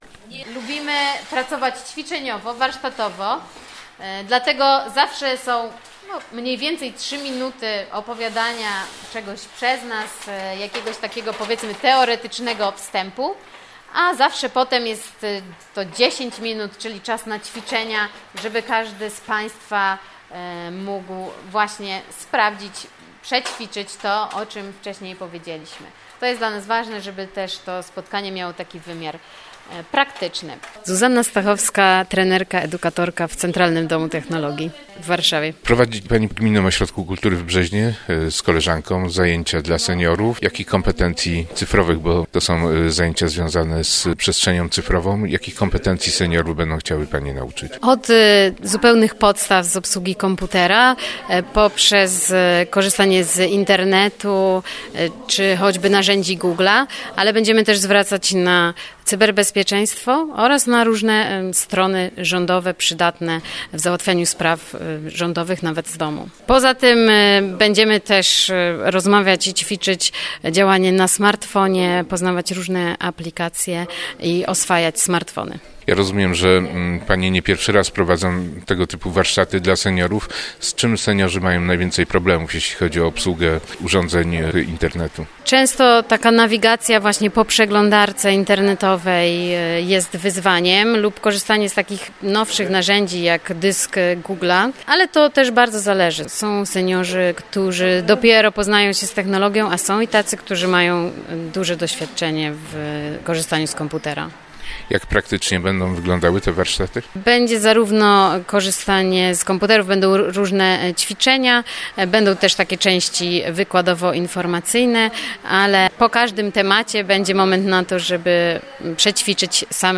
Dotychczas korzystałam tylko ze smartfona – mówi jedna z uczestniczek zajęć.
Oczekujemy dobrej nauki – mówią panowie, którzy również nigdy nie korzystali z komputera.